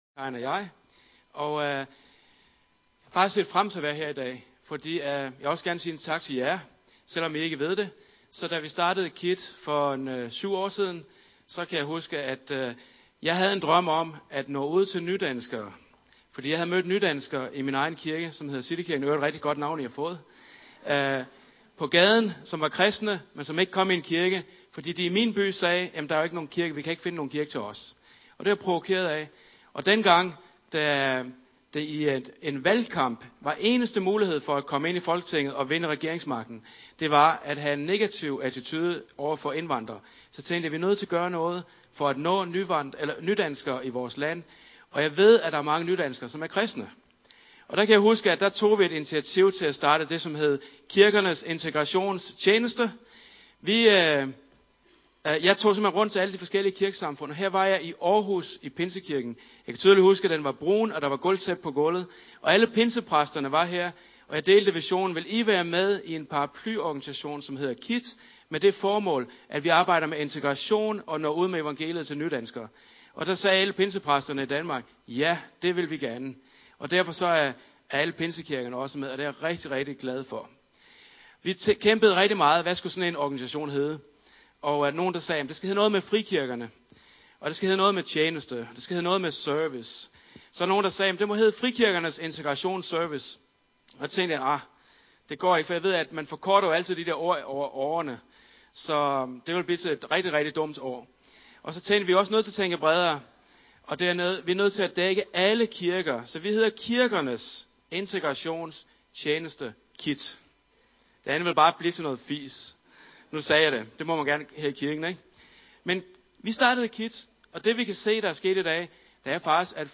Lyt til prædikener - Citykirken Aarhus